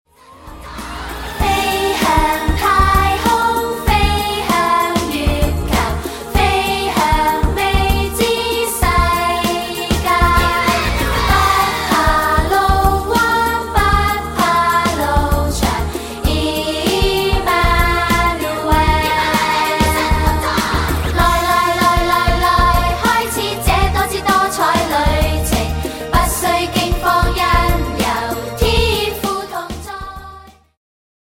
充滿動感和時代感